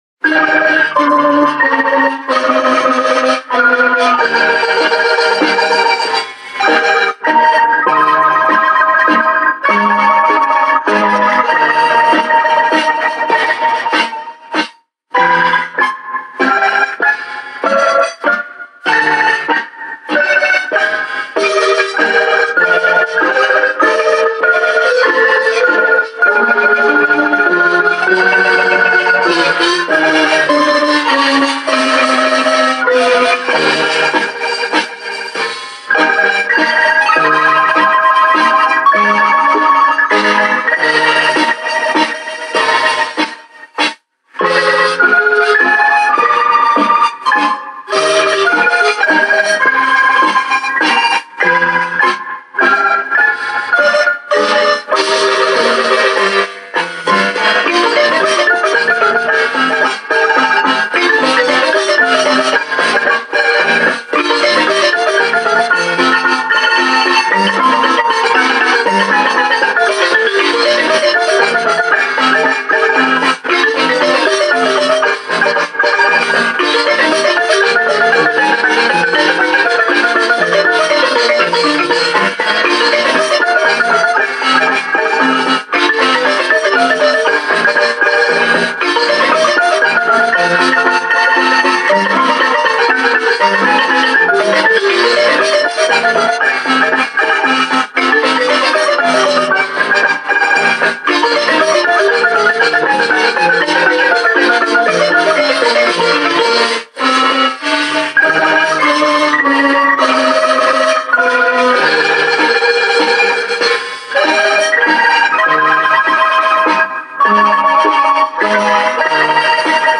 치아파스에서 마림바로 연주되는 Vals Chiapa de Corzo.